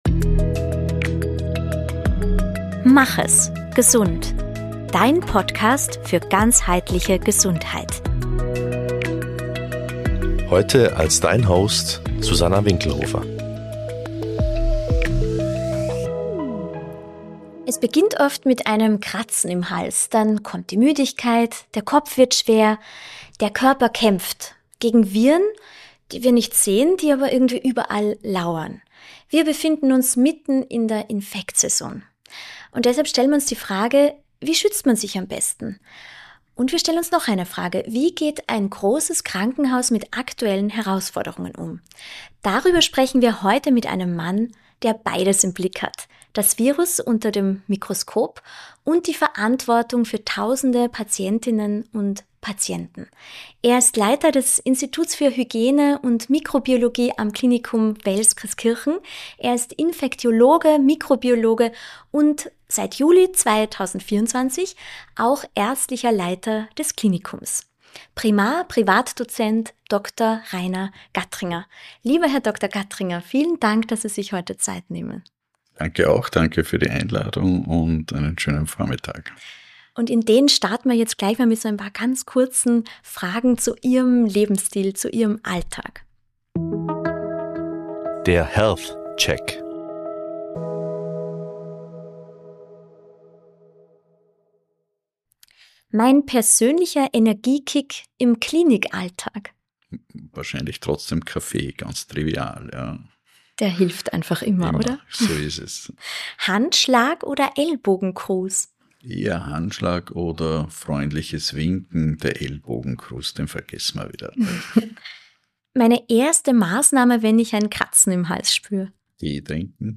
Ein Gespräch voller praktischer Hinweise für die kommenden Monate.